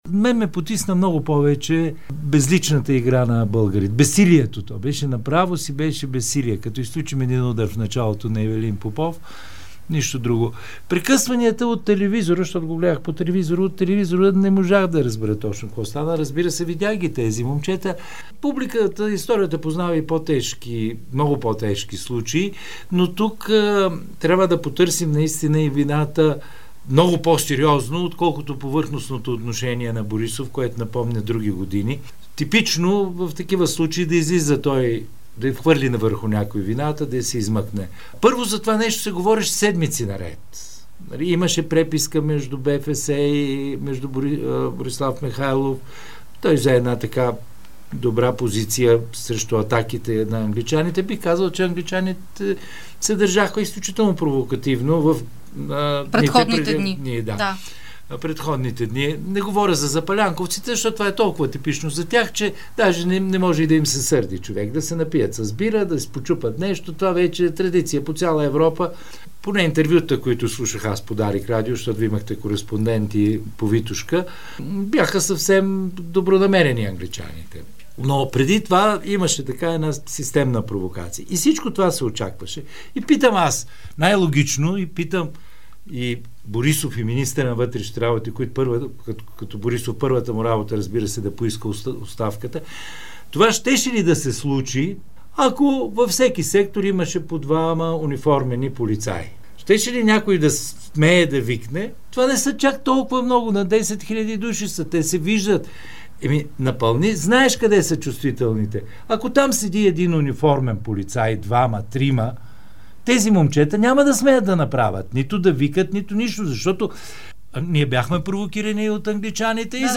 Бившият кмет на София Стефан Софиянски заяви в интервю за Дарик радио, че държавата и общината носят вина за расисткия скандал в мача с Англия на стад... (18.10.2019 16:23:59)